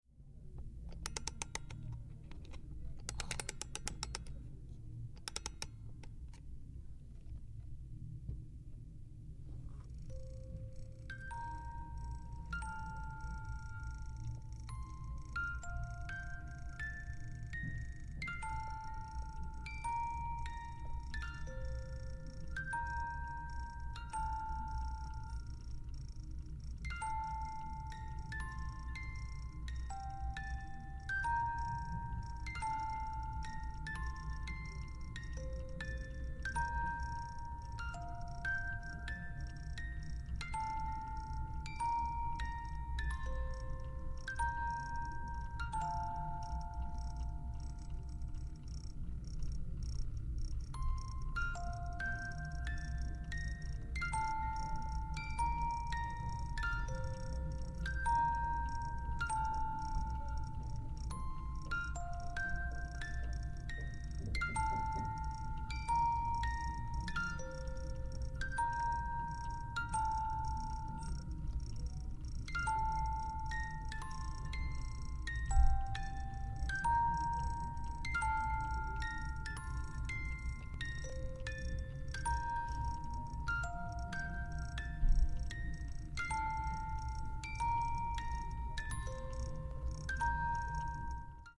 Cuando se hace girar la cuerda un arlequín se mueve al compás de una melodía pausada.